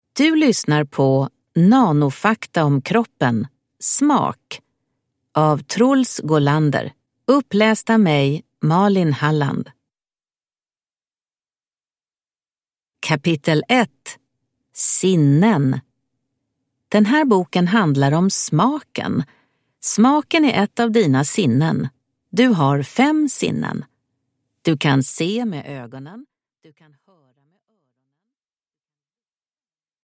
Nanofakta om kroppen. Smak – Ljudbok